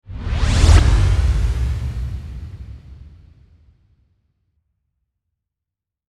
Swoosh Power Up Sound Effect Free Download
Swoosh Power Up